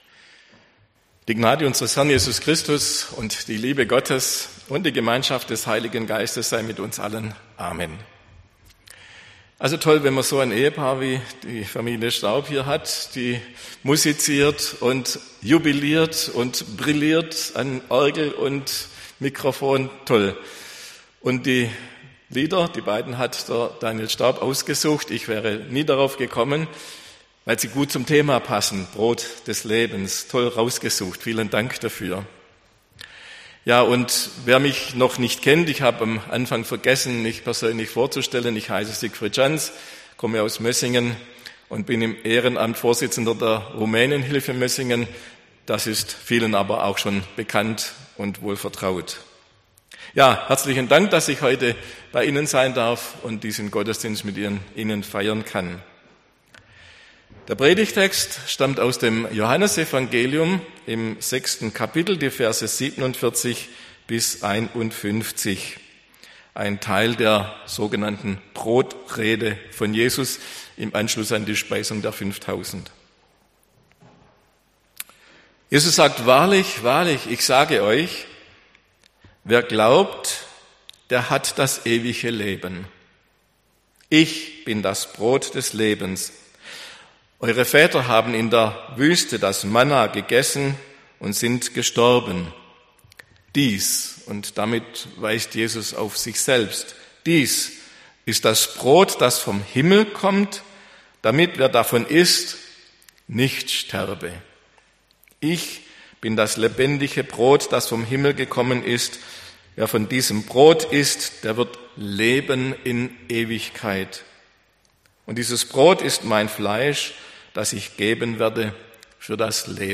Predigt am Sonntag Laetare